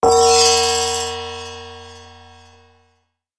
11080 magic gong
bell ding game gong magic metallic notification sound effect free sound royalty free Sound Effects